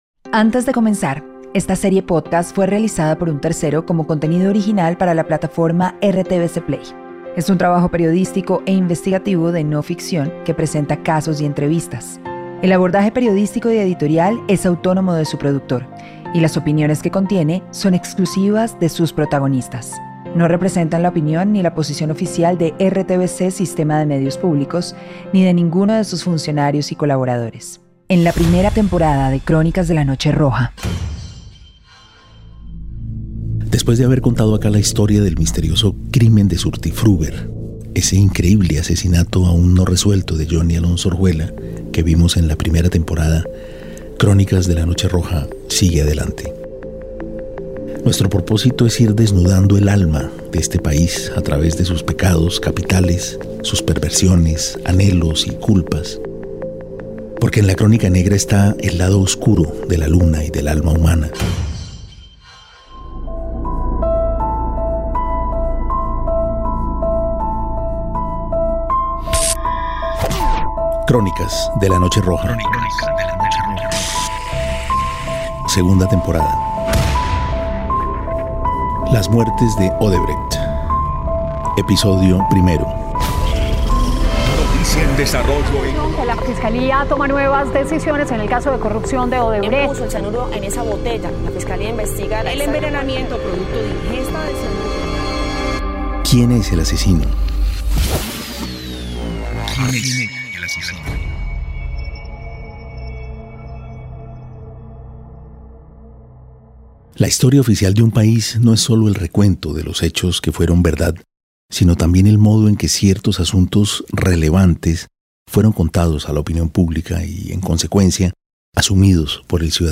Podcast investigación criminal. En esta temporada, Santiago Gamboa busca desenredar los hilos del caso Odebrecht en Colombia.